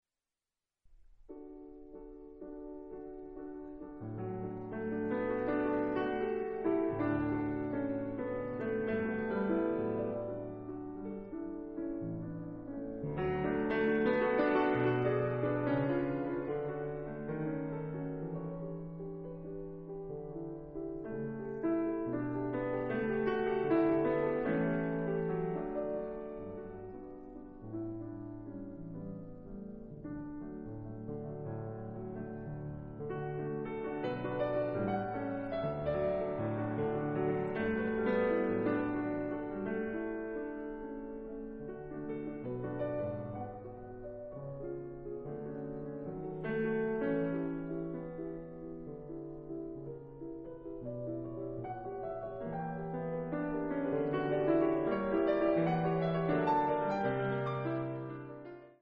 Le lyrisme de la mélodie qui dialogue entre les deux mains est accentué par le mouvement entièrement syncopé de l'accompagnement en accords.